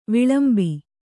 ♪ viḷambi